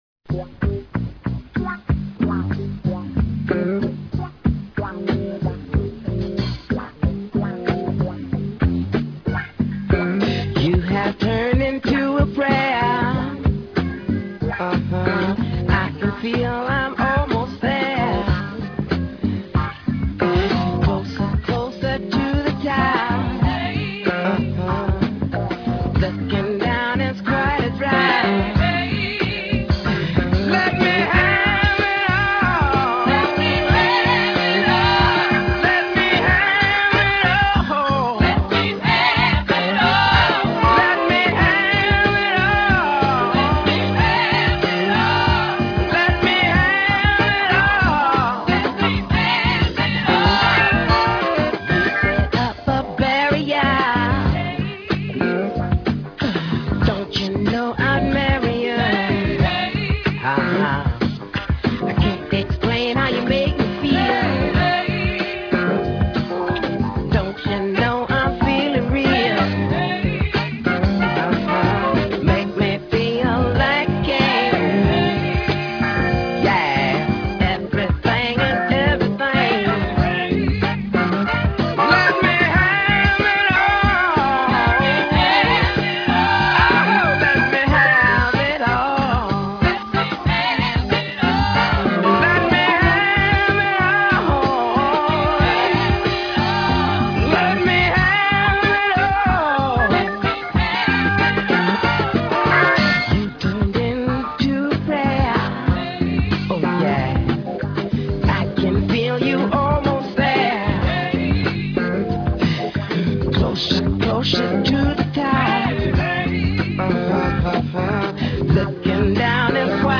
lead vocals, keyboards, everything
guitar, background vocals
lead and background vocals
drums
saxophone
trumpet
bass guitar